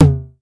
Tuned drums (C# key) Free sound effects and audio clips
• Short Tom Sound C# Key 06.wav
Royality free tom tuned to the C# note. Loudest frequency: 735Hz
short-tom-sound-c-sharp-key-06-hfU.wav